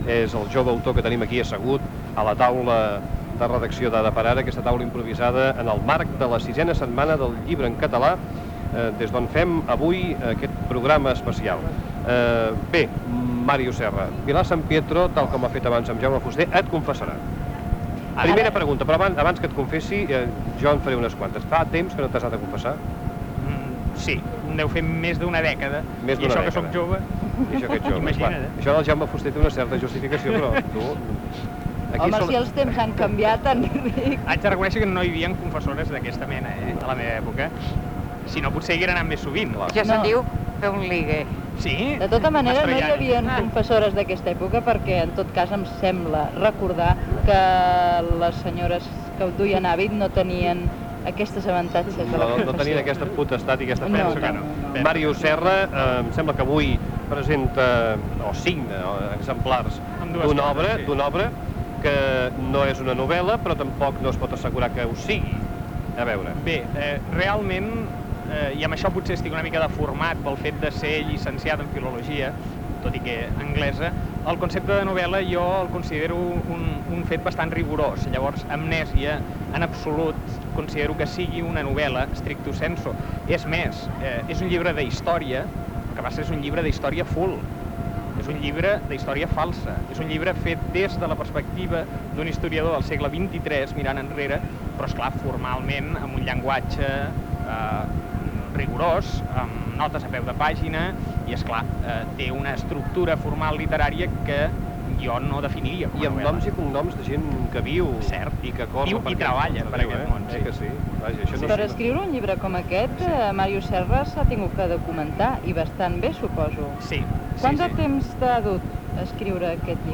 Programa especial fet des de la VI Setmana del Llibre en Català. Entrevista a l'escriptor Màrius Serra que ha publicat el llibre "Amnèsia"
Info-entreteniment